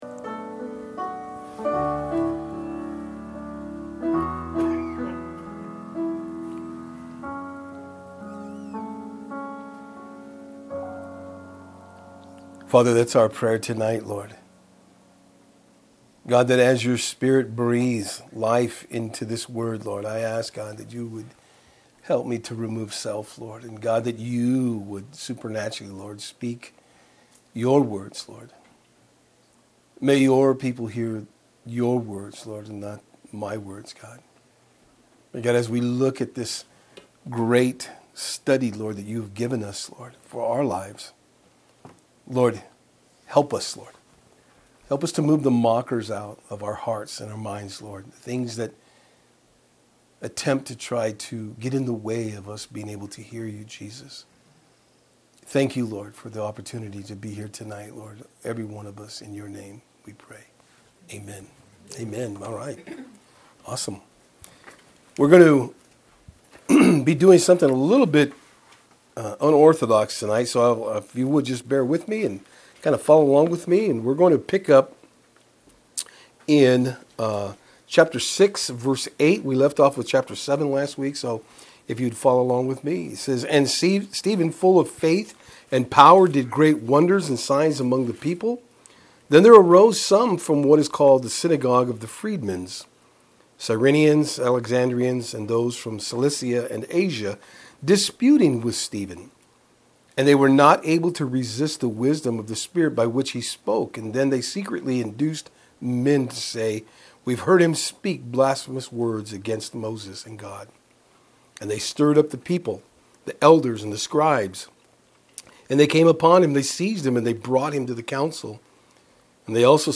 In this lesson we are going to look at how God who works in and through His people, brings them to the place of furtherance of the Gospel.
Service Type: Saturdays on Fort Hill